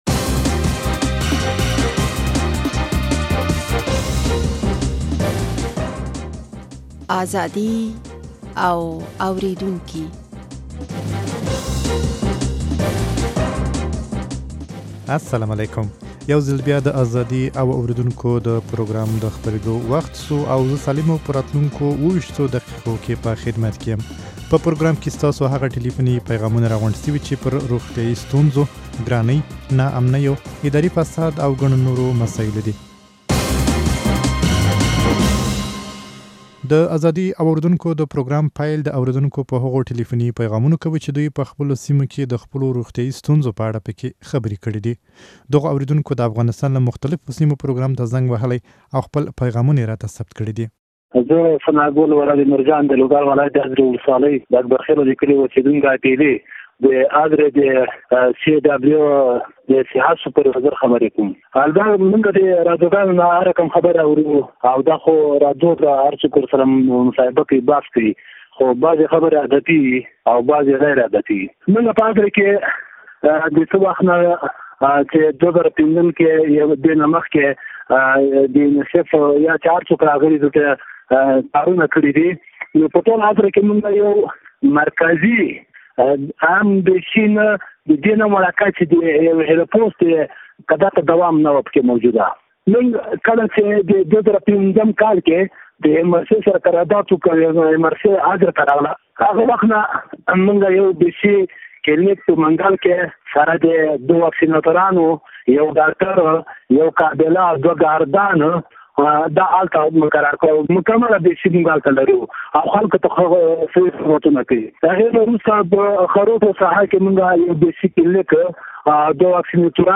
په پروګرام کې ستاسو هغه ټليفوني پيغامونه راغونډ شوي چې پر روغتيايي ستونزو، ګرانۍ، ناامنيو، اداري فساد او ګڼو نورو مسايلو دي.